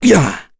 Dakaris-Vox_Attack5.wav